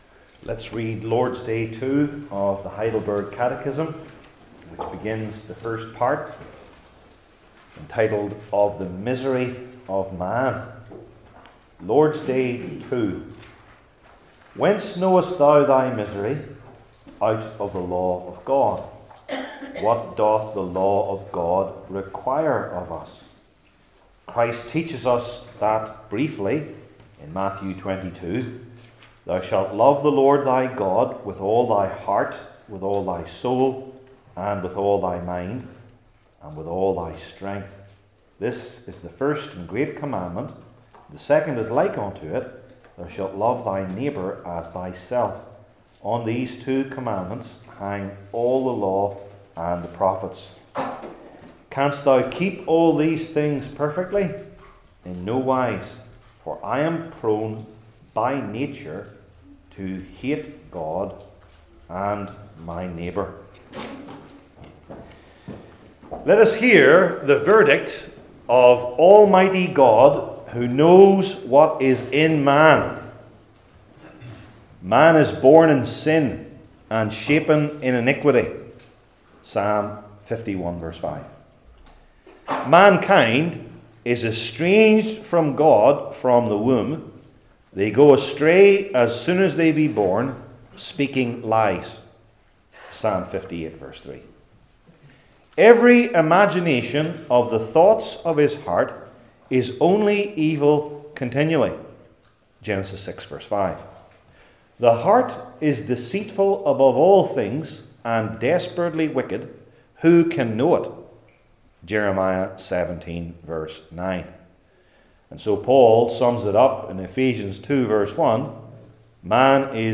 Heidelberg Catechism Sermons I. The Meaning II.